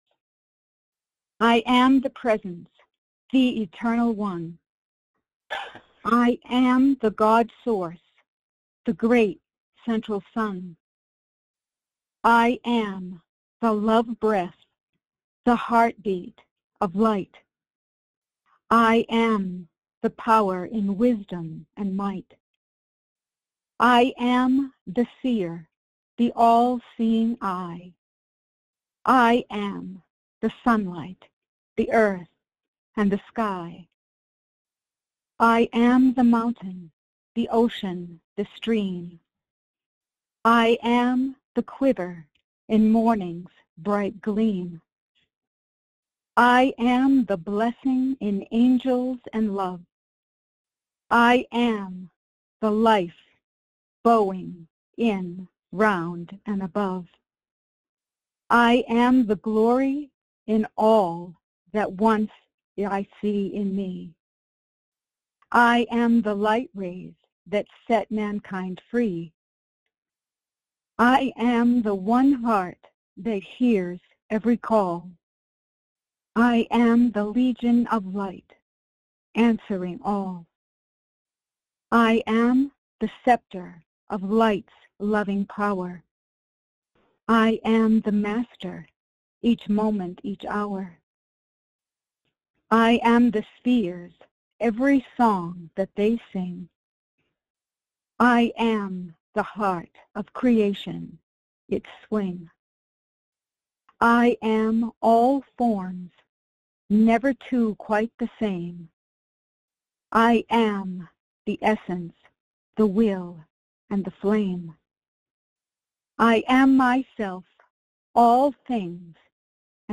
Join in group meditation with Archangel Michael